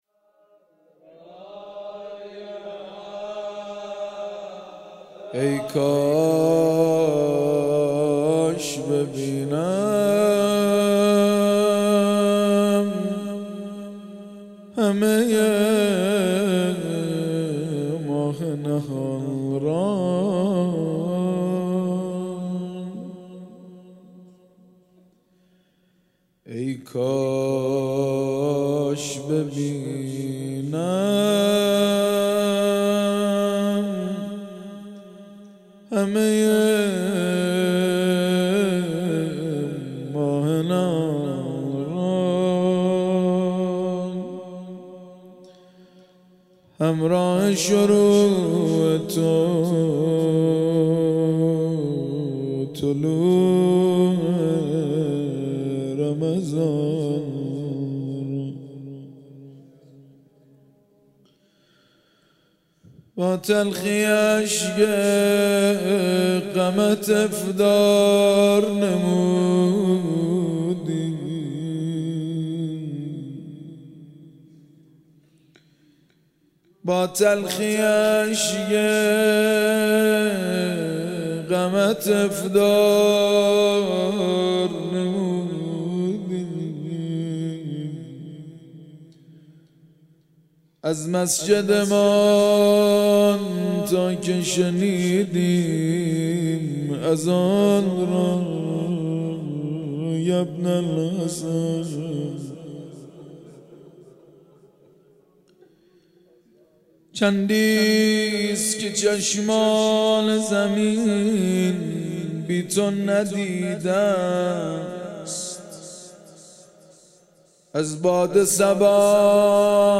شب سوم ماه مبارک رمضان
در مسجد کربلا واقع در بزرگراه صدر – غرب به شرق برگزار گردید.
بخش اول:غزل بخش دوم:مناجات لینک کپی شد گزارش خطا پسندها 0 اشتراک گذاری فیسبوک سروش واتس‌اپ لینکدین توییتر تلگرام اشتراک گذاری فیسبوک سروش واتس‌اپ لینکدین توییتر تلگرام